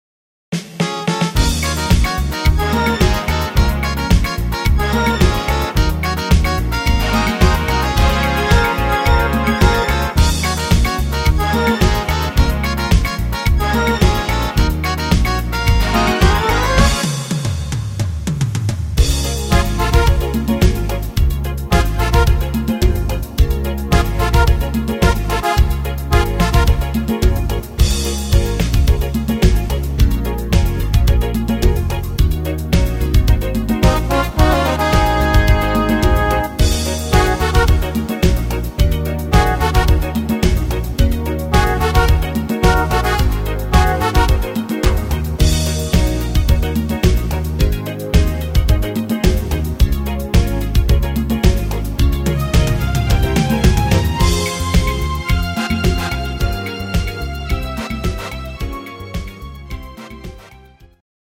Rhythmus  Samba
Art  Oldies, Spanisch